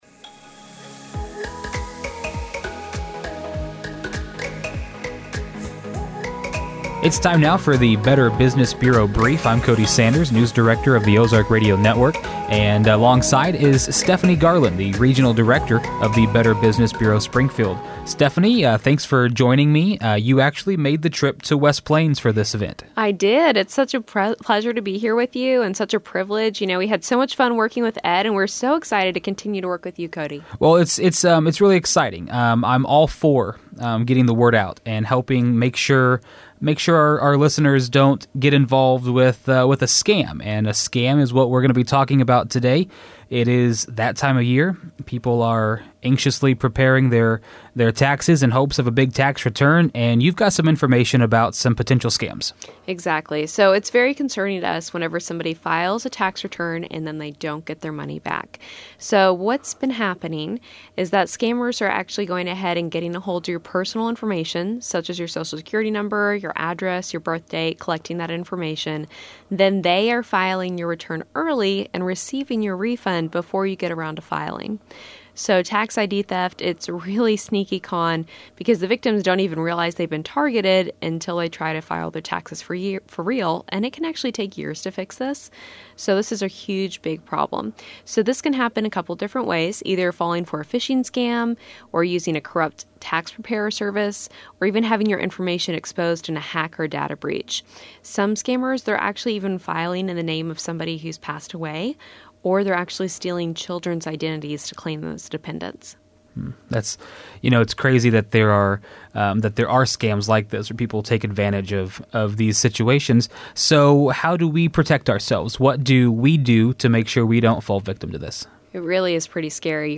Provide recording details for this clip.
The Ozark Radio Network and the Better Business Bureau of Southwest Missouri have joined together to offer consumer safety tips, alerts, and warnings in a weekly segment called the BBB Break.